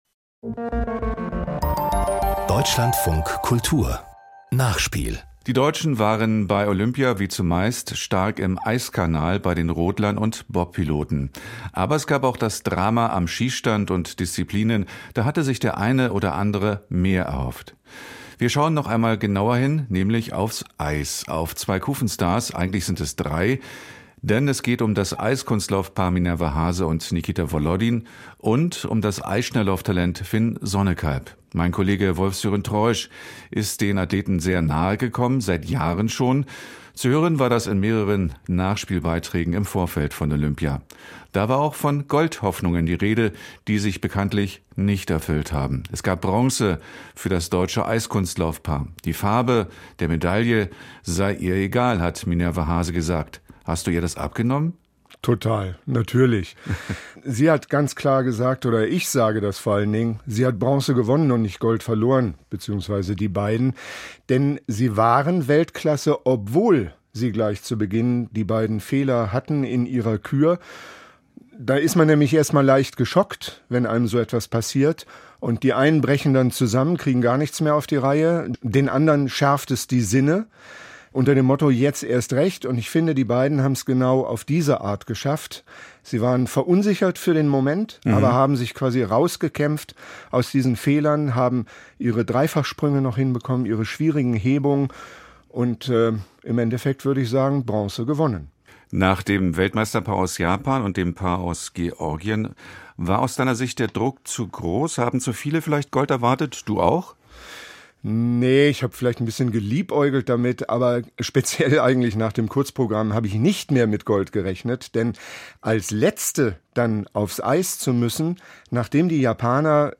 Deutsche Kufenstars - Bilanzgespräch